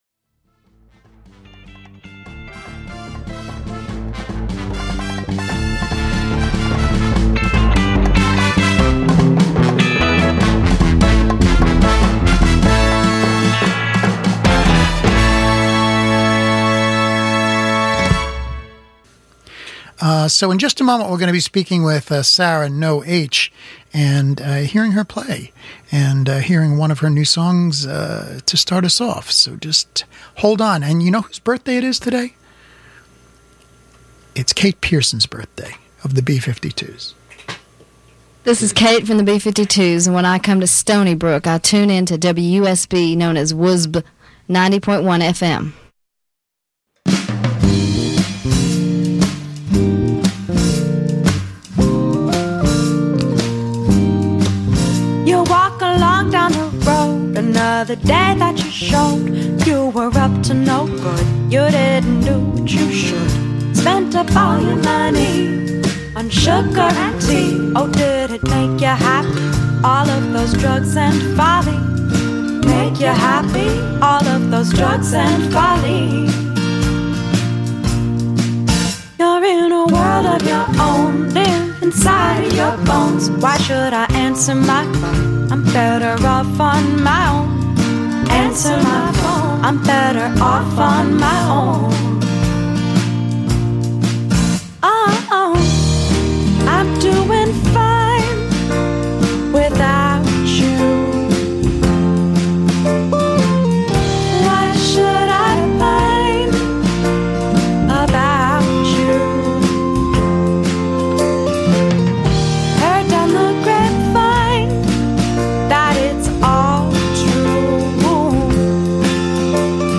Interview with singer/songwriter
(live on WUSB)